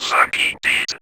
VVE1 Vocoder Phrases
VVE1 Vocoder Phrases 19.wav